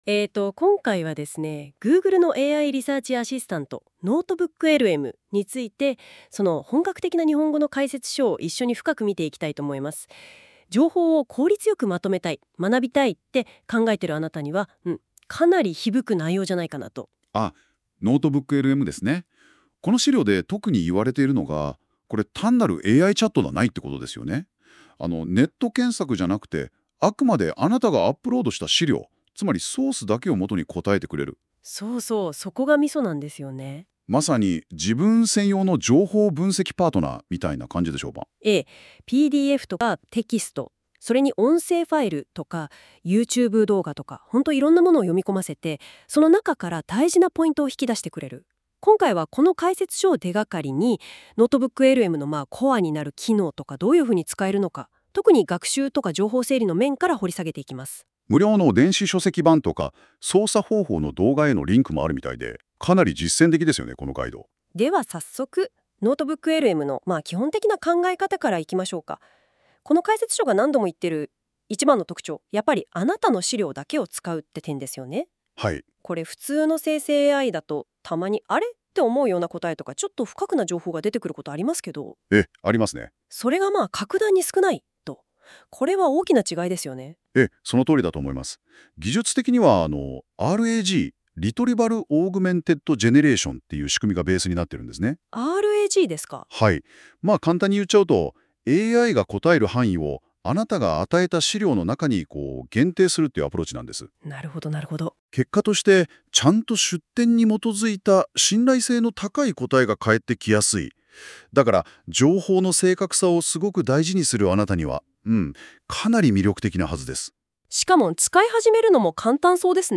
ポッドキャスト風の要約が作れる「音声解説」
ボタンをワンクリックするだけで、資料の内容について2人の人物が会話するポッドキャスト風の音声を作成できます。
Google_NotebookLM音声解説.m4a